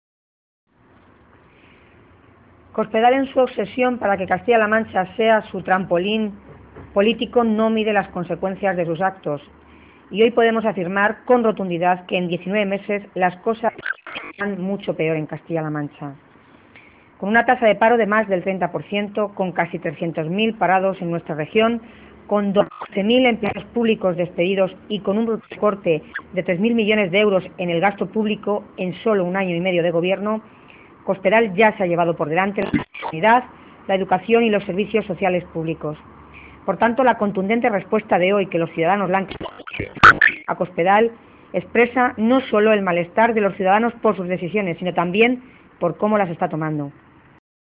Tolón hacía estas manifestaciones mientras participaba en la manifestación convocada hoy en la capital regional por hasta 8 centrales sindicales y que bajo el lema “En defensa de los servicios públicos”, ha protestado contar los recortes en los servicios públicos y los despidos del Gobierno regional.
Cortes de audio de la rueda de prensa